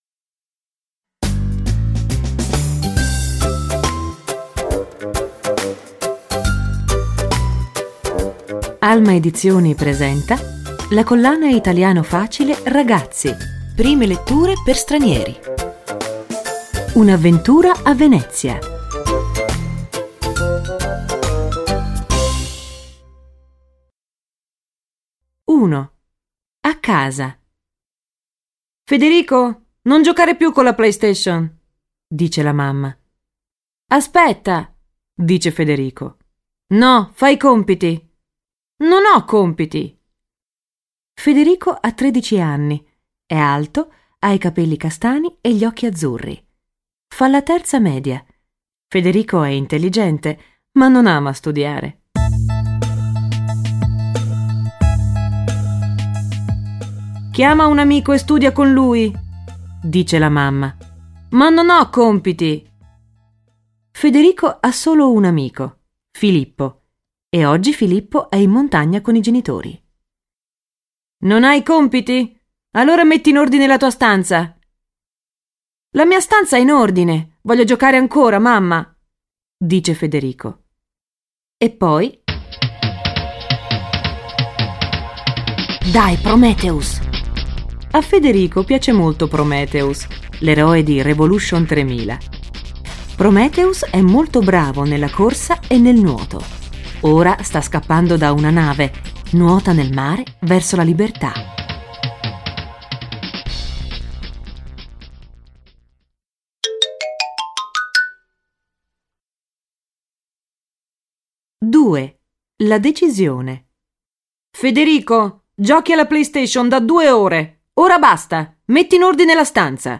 Il volume fa parte della collana Italiano facile per ragazzi, letture graduate con esercizi e versione audio del testo, con voci di attori professionisti ed effetti sonori realistici e coinvolgenti.